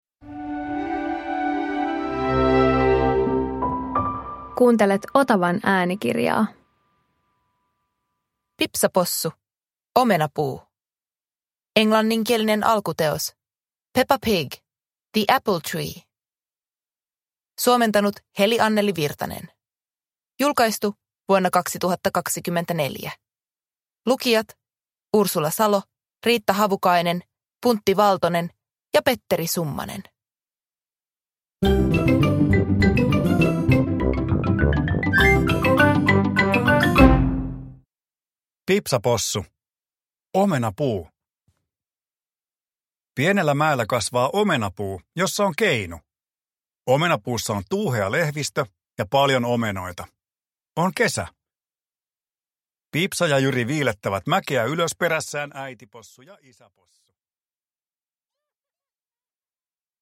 Pipsa Possu - Omenapuu – Ljudbok